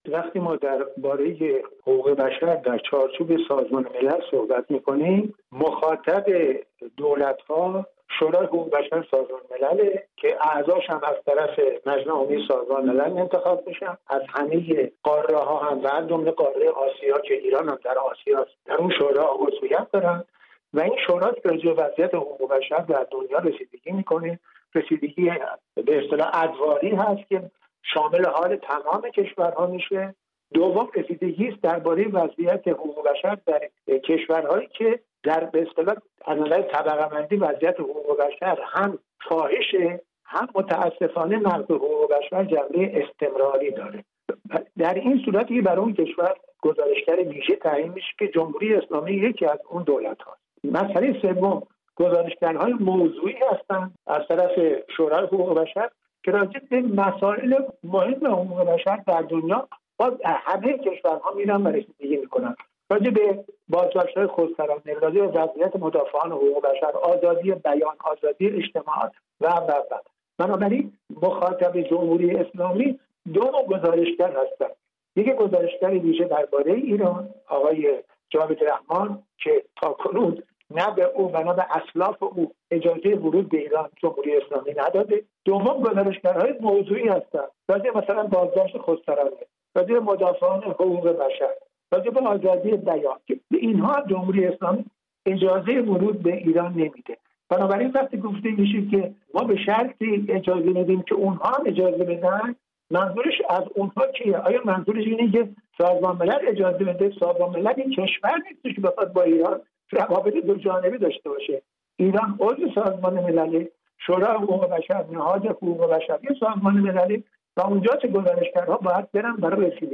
رئیس قوه قضاییه جمهوری اسلامی، نقض حقوق بشر در ایران را ادعایی دروغین خوانده و اجازه بازدید از زندان‌های ایران را مشروط به این کرده که مقام‌های جمهوری اسلامی هم اجازه داشته باشند از زندان‌های کشورهای دیگر بازدید کنند. گفت‌وگوی